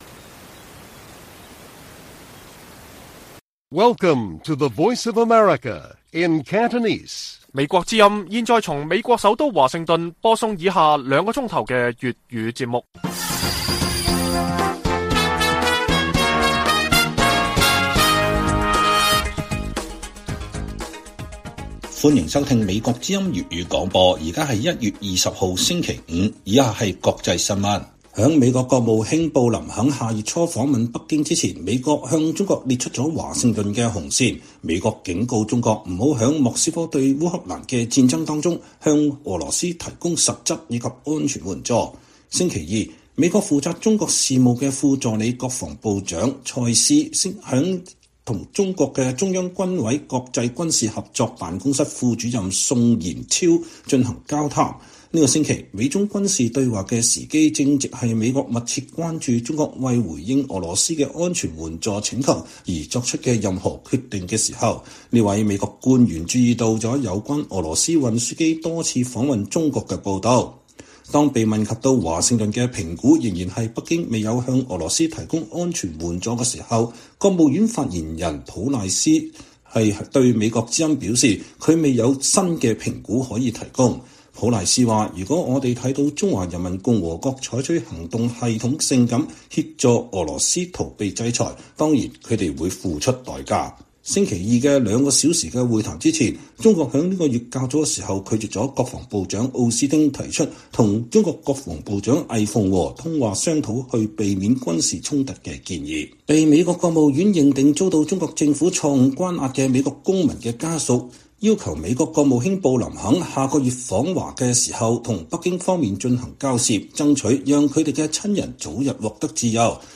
粵語新聞 晚上9-10點：布林肯訪華前美國就烏克蘭戰爭紅線警告中國